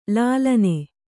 ♪ lālane